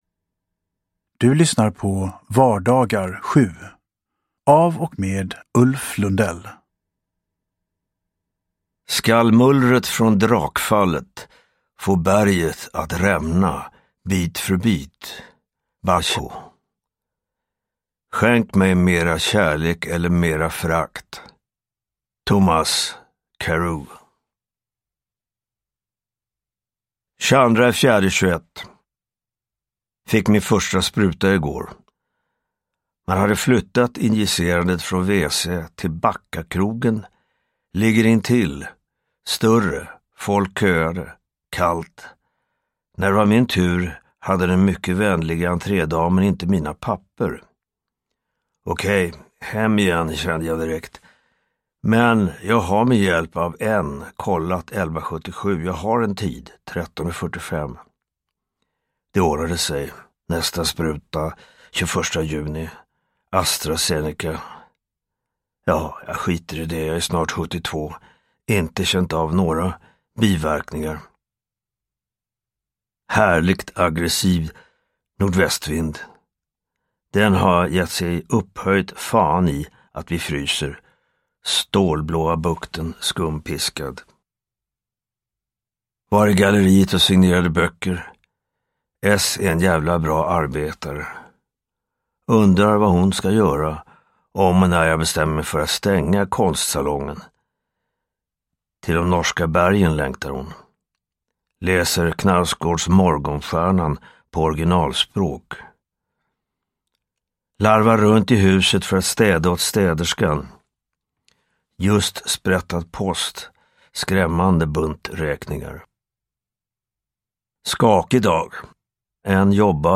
Vardagar 7 – Ljudbok – Laddas ner